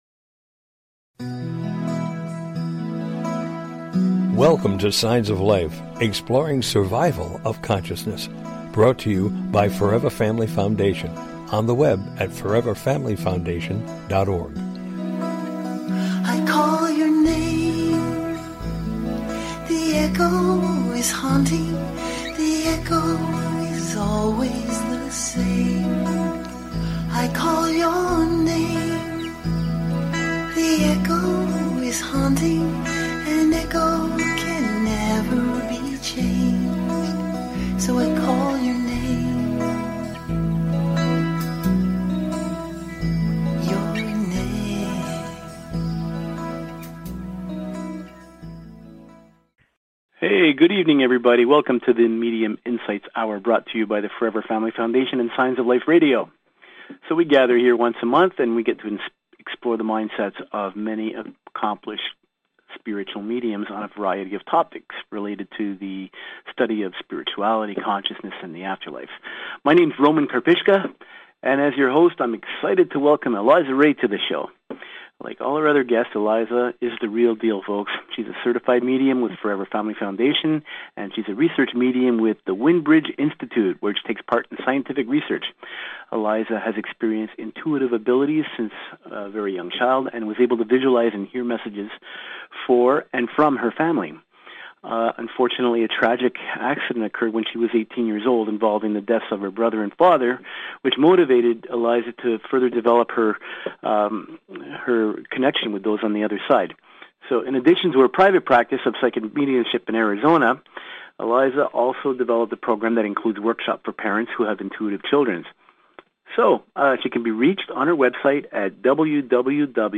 Signs of Life Radio Show is a unique radio show dedicated to the exploration of Life After Death!
Call In or just listen to top Scientists, Mediums, and Researchers discuss their personal work in the field and answer your most perplexing questions.